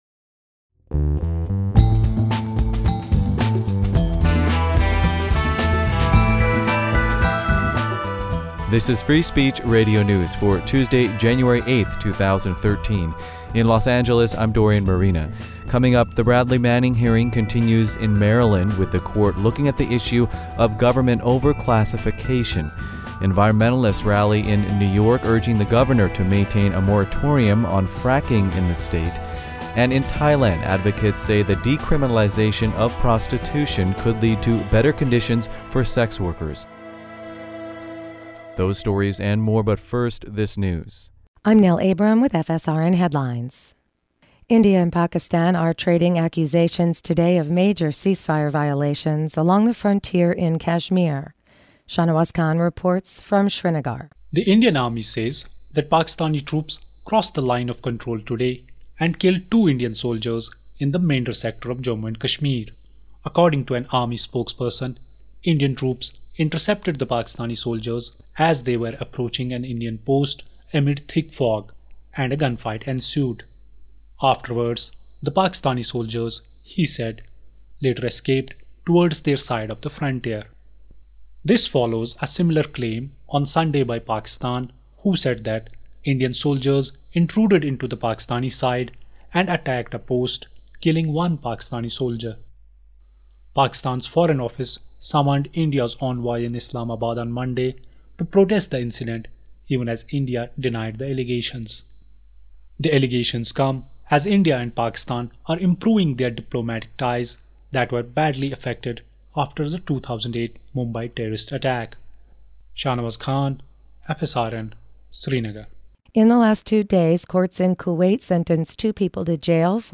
Newscast for Tuesday, January 8, 2013